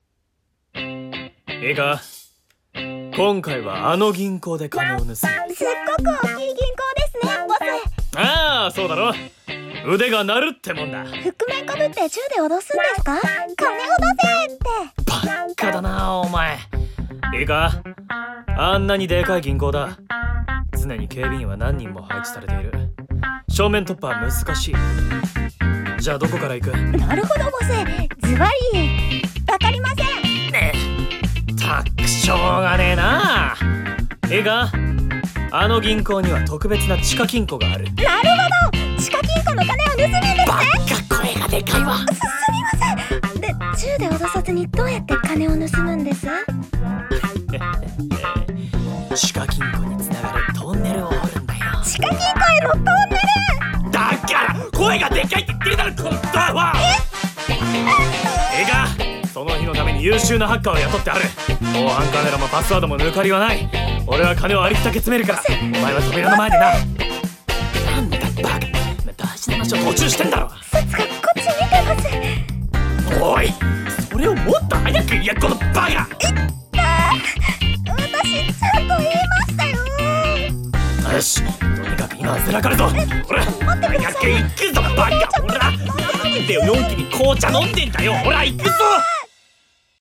【声劇】フールトューラバー！【掛け合い】